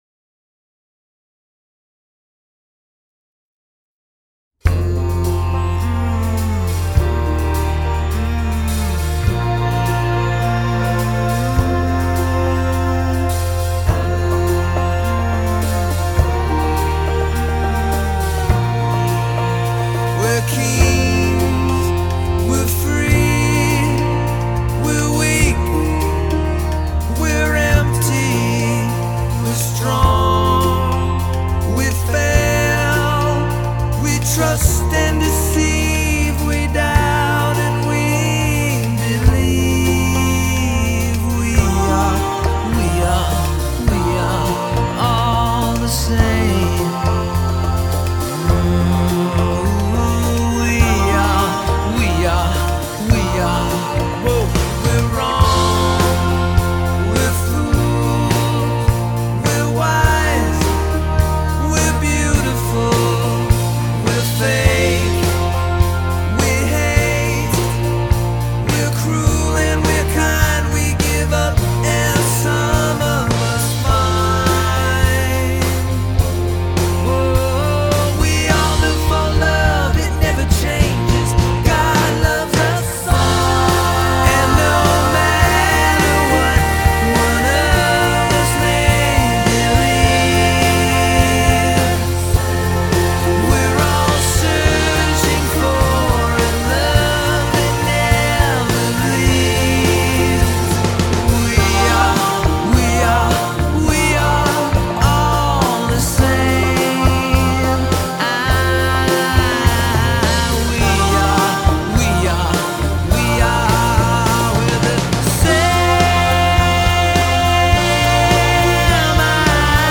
The song is mellow, yet haunting in its own way